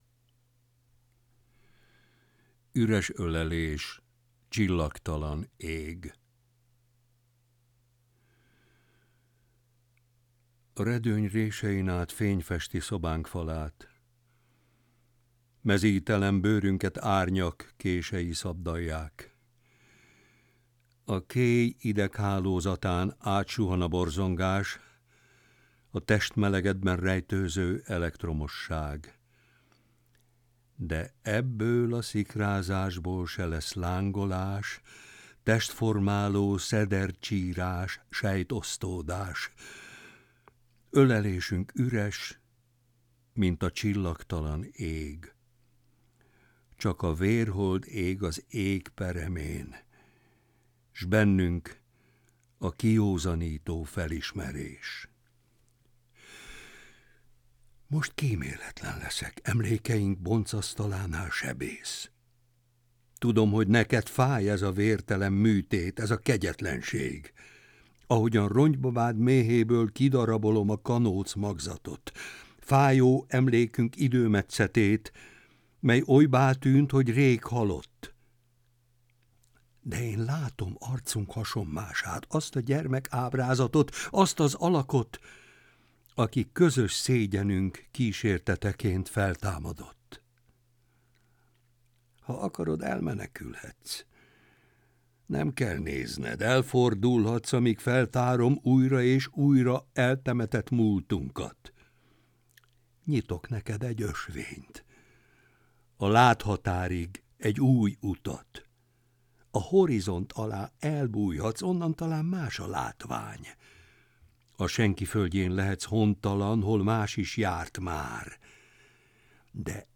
*Versolvasó: Barbinek Péter